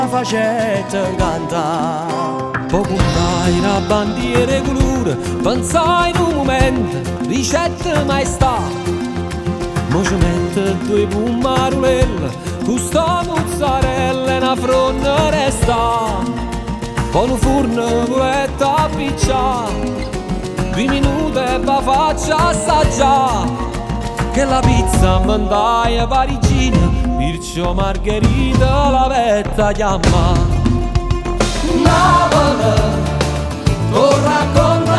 Adult Contemporary Pop Rock Rock Adult Alternative
Жанр: Поп музыка / Рок / Альтернатива